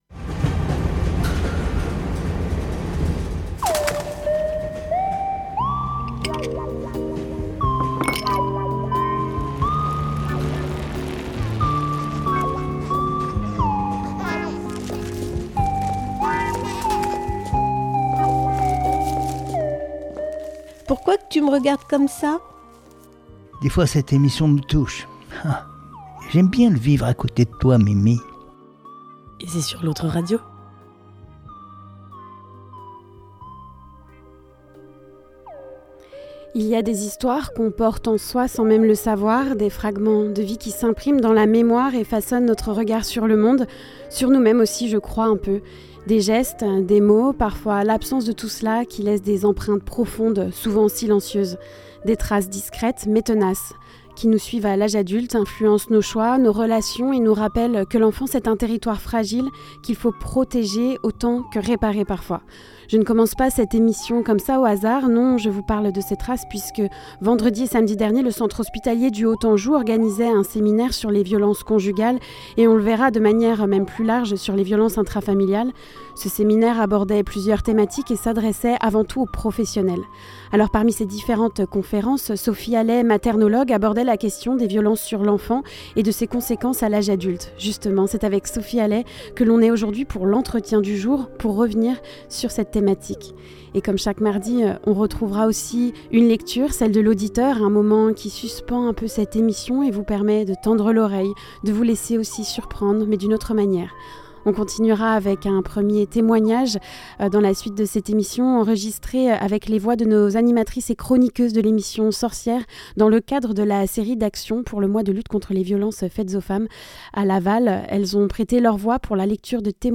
A l'occasion des actions menées autour de la journée internationale de lutte contre les violences faites aux femmes, sur Laval Agglo. 11 témoignages écrits de femmes ont été recueillis par L'Autre Radio et ont ensuite été lu par les membres de l'équipe Sorcières.
La lecture de l'auditeur